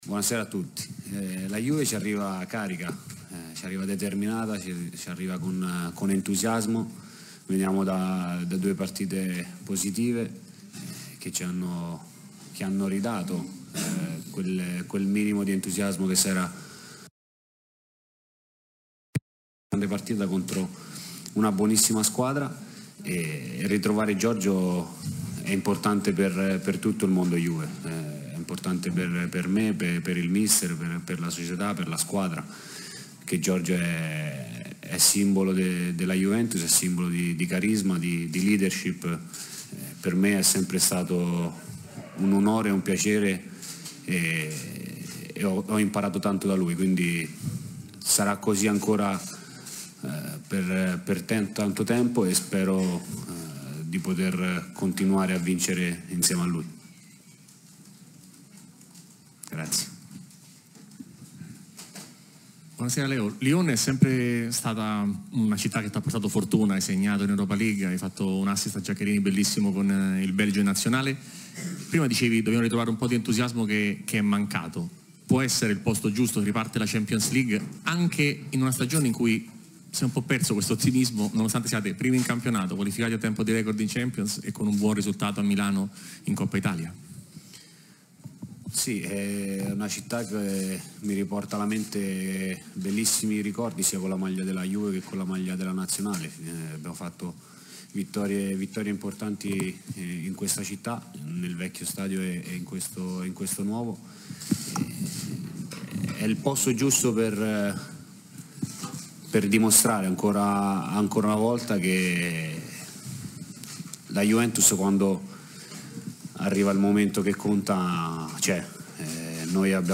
Conferenza stampa di Leonardo Bonucci e Maurizio Sarri verso la sfida contro il Lione, in scena domani al Parc OL. © registrazione di Radio Bianconera Facebook twitter Altre notizie